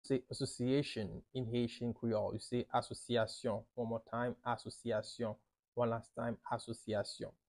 “Association” in Haitian Creole – “Asosyasyon” pronunciation by a native Haitian tutor
“Asosyasyon” Pronunciation in Haitian Creole by a native Haitian can be heard in the audio here or in the video below:
How-to-say-Association-in-Haitian-Creole-–-Asosyasyon-pronunciation-by-a-native-Haitian-tutor.mp3